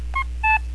Click on the picture to hear a recording of the coocoo sound.
coocoo.wav